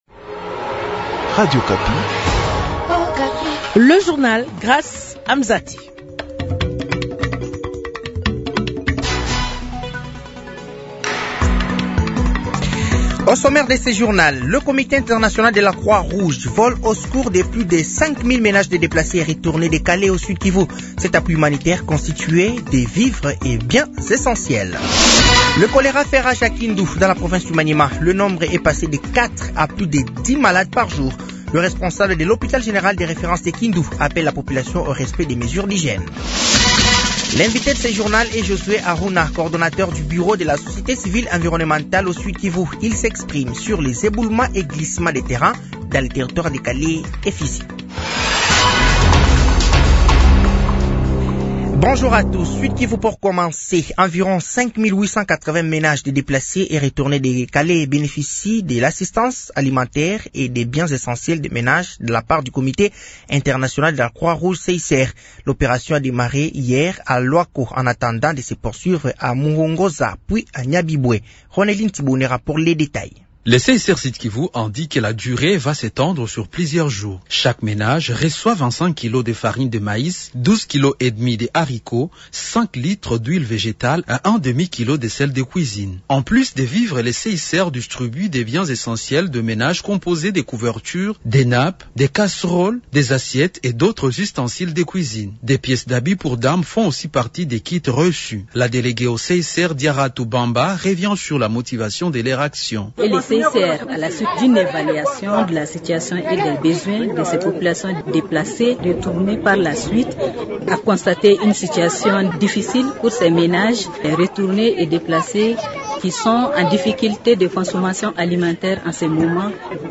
Journal français de 15h de ce mercredi 14 mai 2025